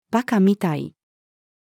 馬鹿みたい-female.mp3